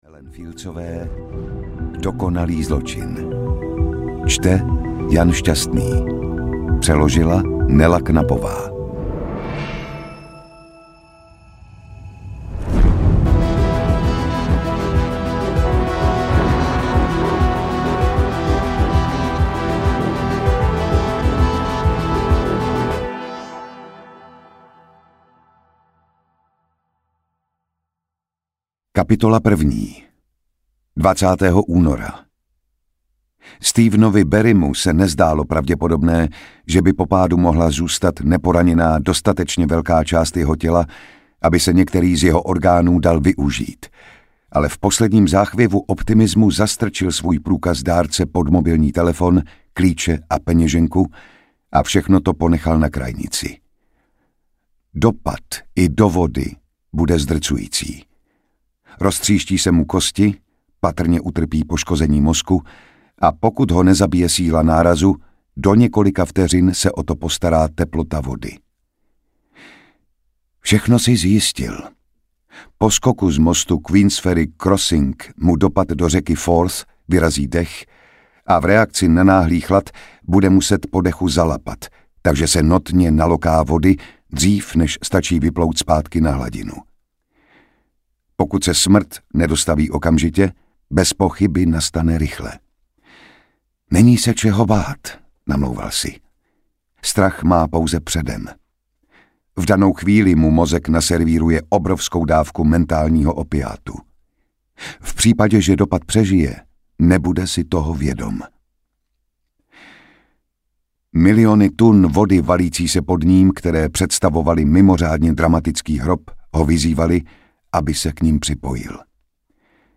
Dokonalý zločin audiokniha
Ukázka z knihy
• InterpretJan Šťastný